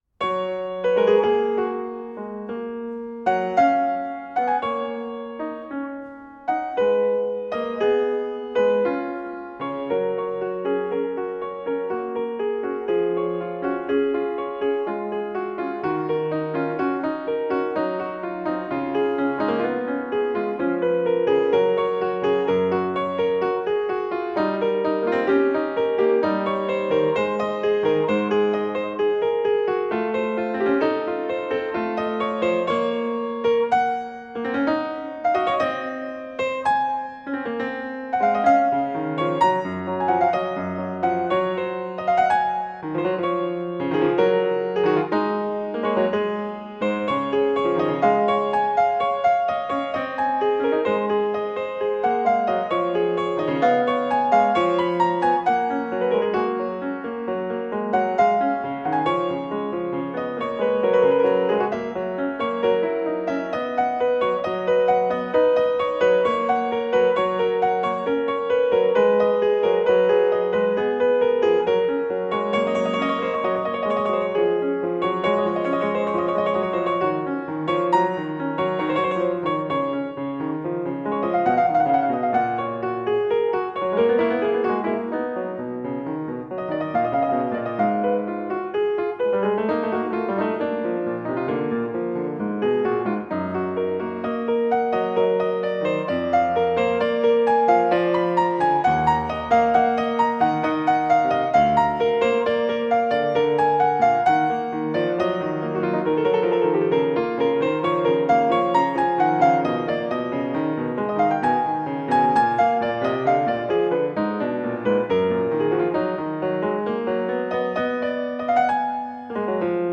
Piano  (View more Advanced Piano Music)
Classical (View more Classical Piano Music)